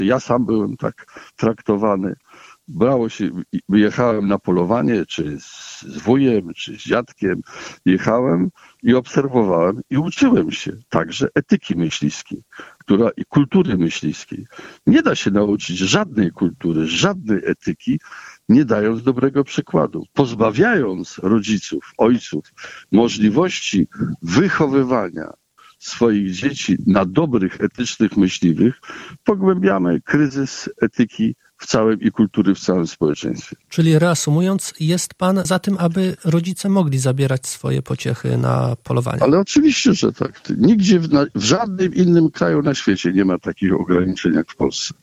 Swoje zdanie przedstawił w audycji „Gość Radia 5”.